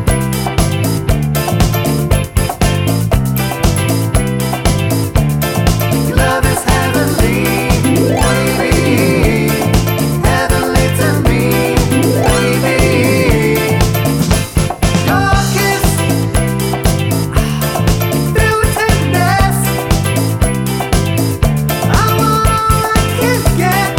One Semitone Down Disco 3:36 Buy £1.50